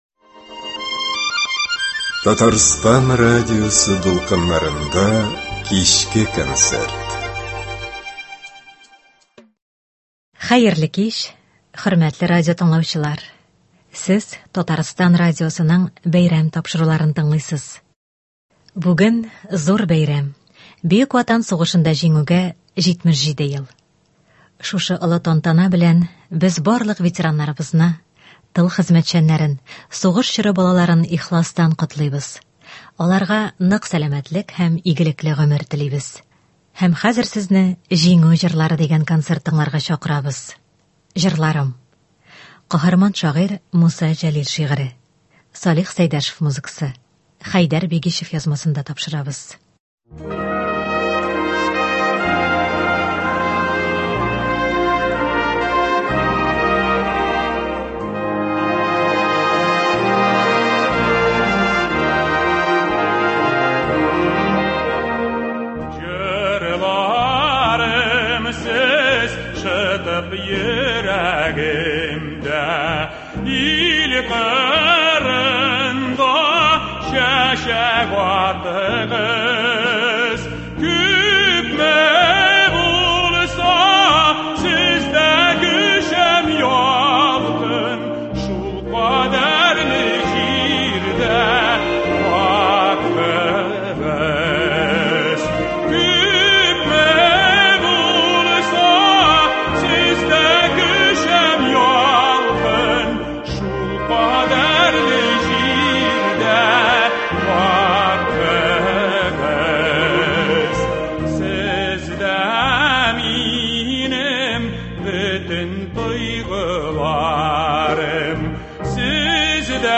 Көндезге концерт. Солдат җырлары.